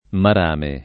marame [ mar # me ] s. m.